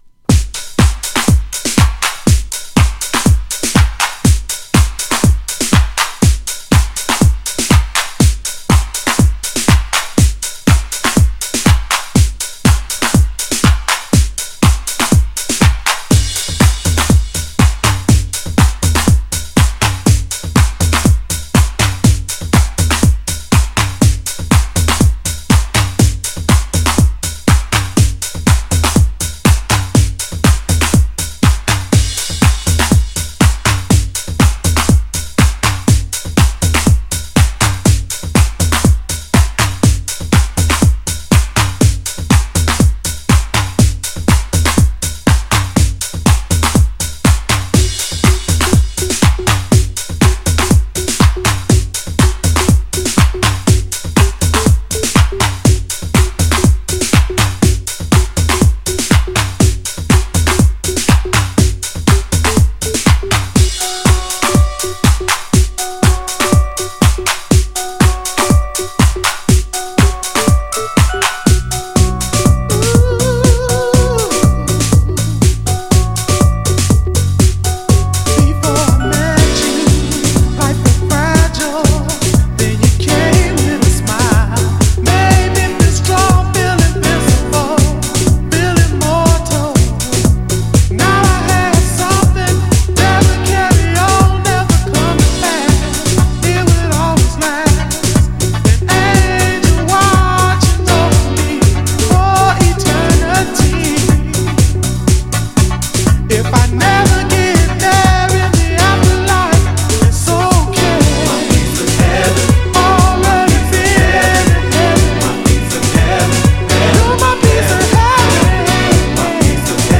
ドラマティックなプレイ用に
GENRE House
BPM 121〜125BPM
エモーショナル # シンセ # ダビー